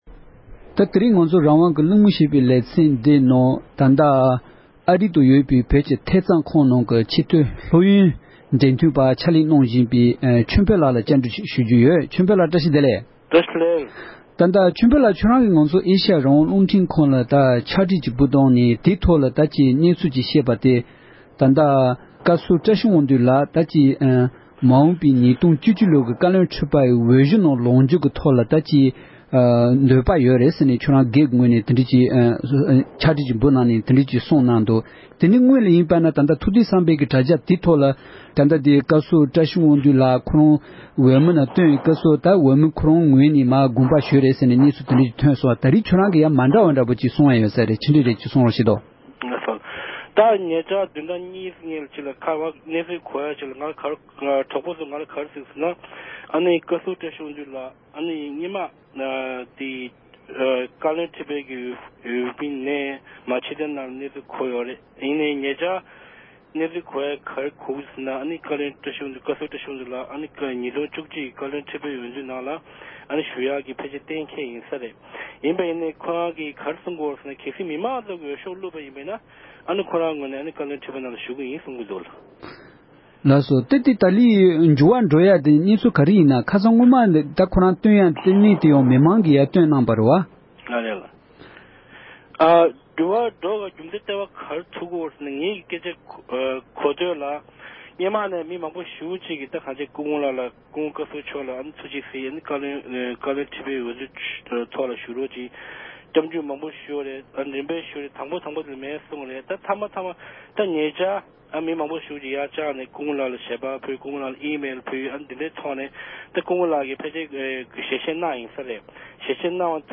སྐུ་ངོ་ལ་བཀའ་འདྲི་ཞུས་པ།